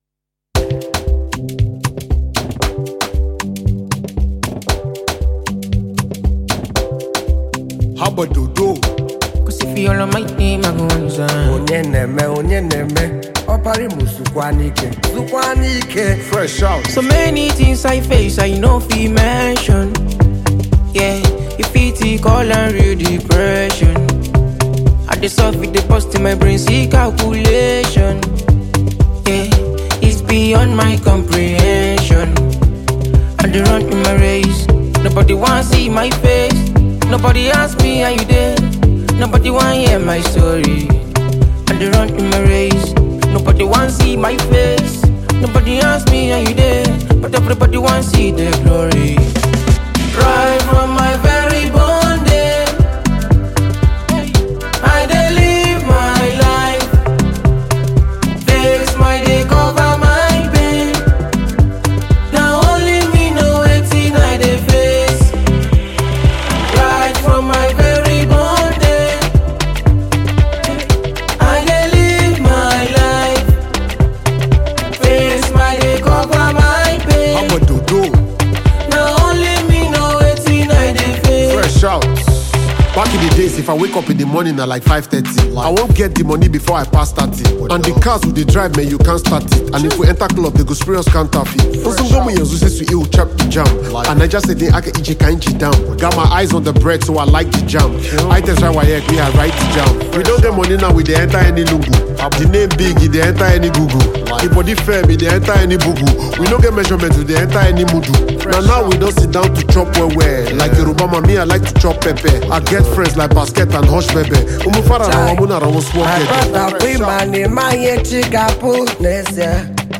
Fast-rising Nigerian singer
whom both added extra vocals and melodies to the hit track.